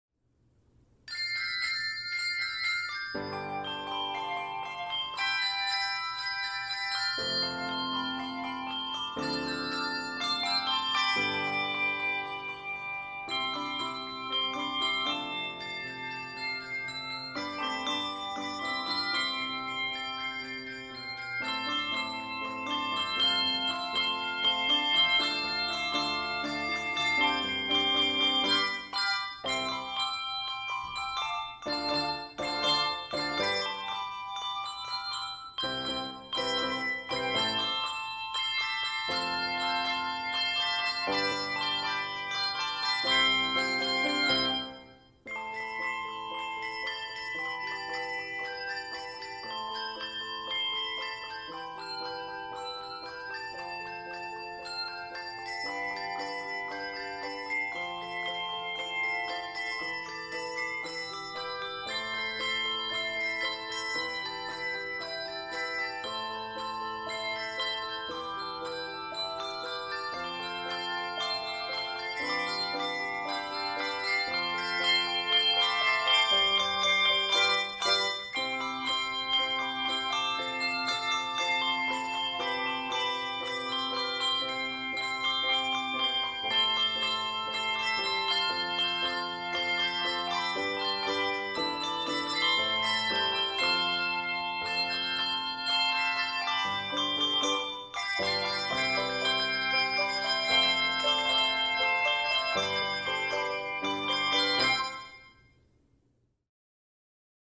Piano accompaniment is optional.